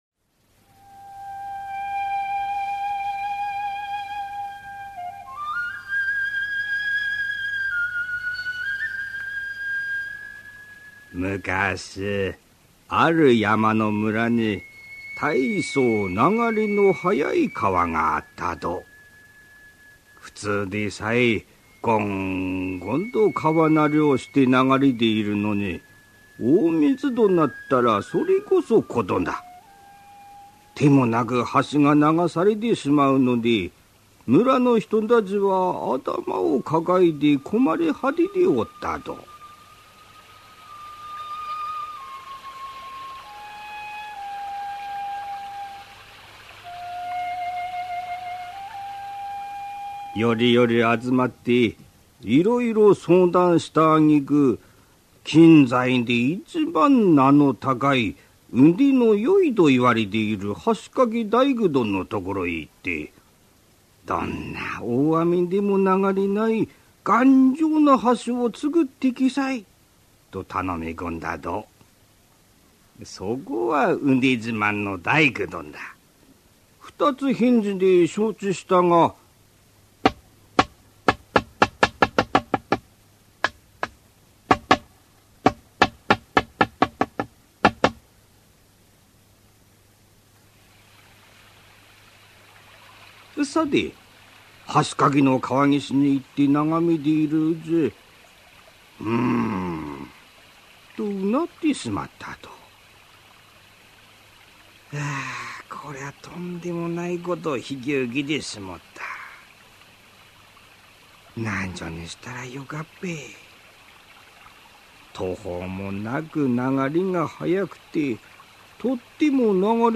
[オーディオブック] 大工と鬼六